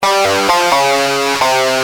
Lead_b3.wav